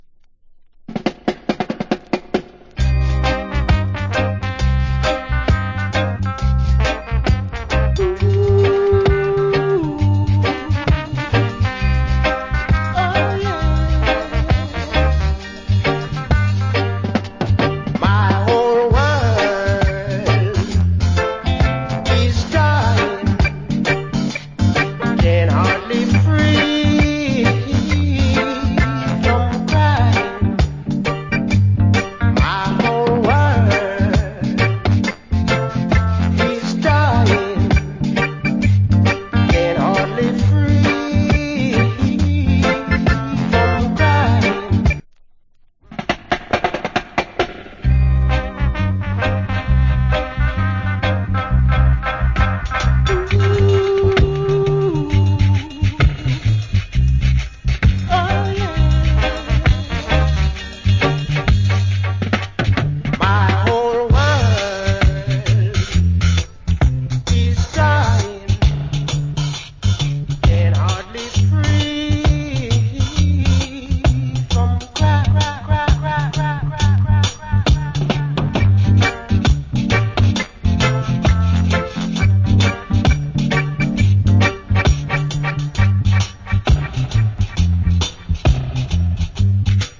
Cool Roots Rock Vocal.